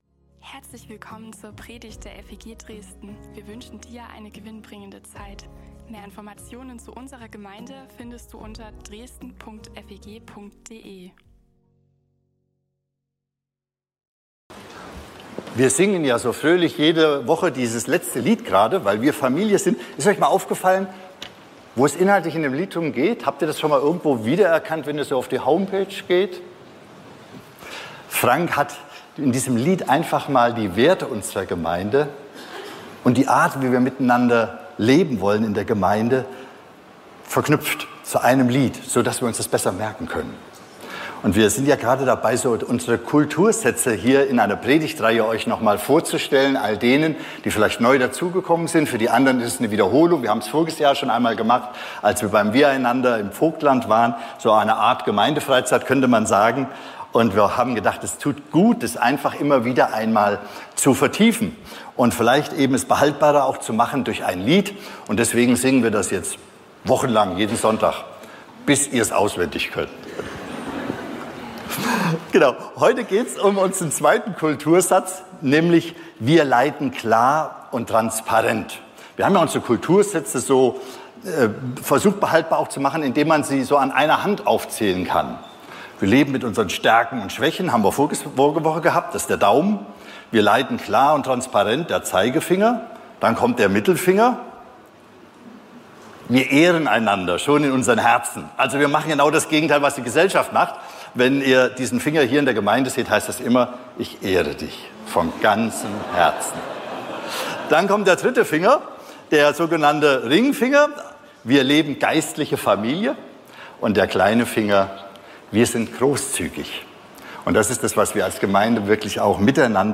Predigten und mehr